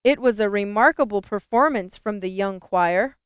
• Audio Bandwidth: 0 to 4 kHz at 8 kHz sampling rate
Example Audio file with Cancelled Acoustic Echo - 64mSec 16 Mar 2016
Example Audio file with Cancelled Acoustic Echo - 64mSec.wav